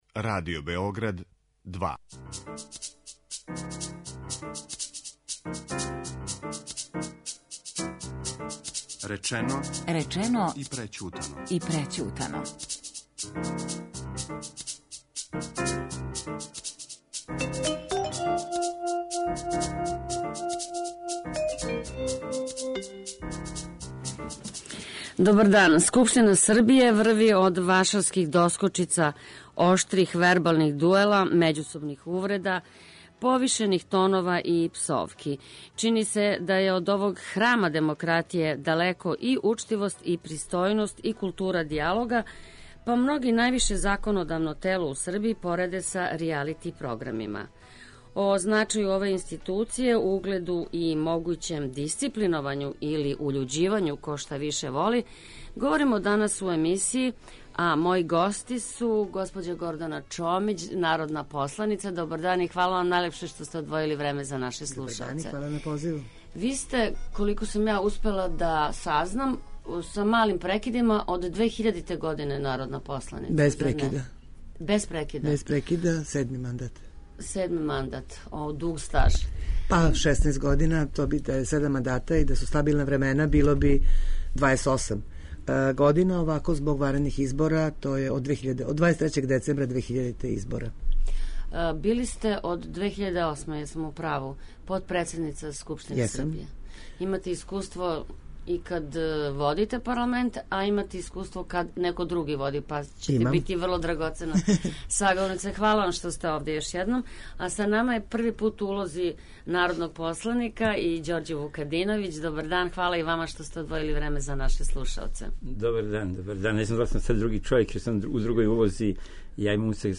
У емисији учествују народни посланици Гордана Чомић, Ђорђе Вукадиновић